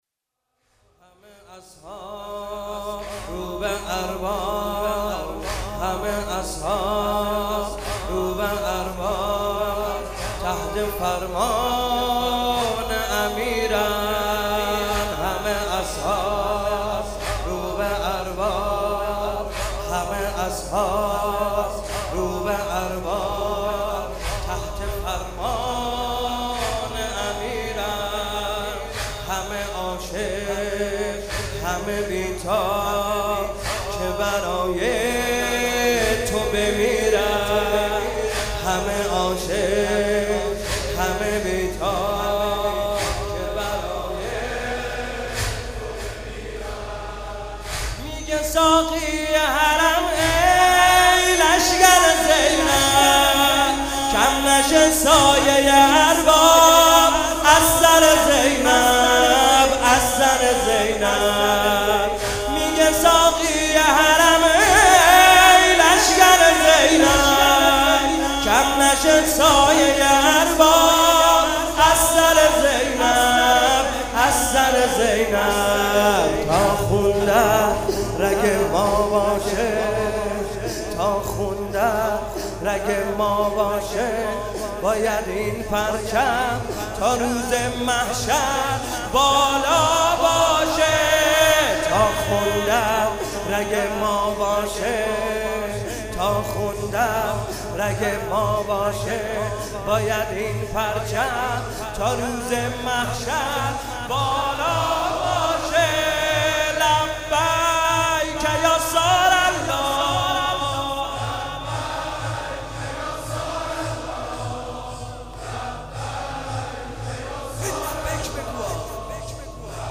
شب دوم محرم - ورود کاروان به کربلا
حسین طاهری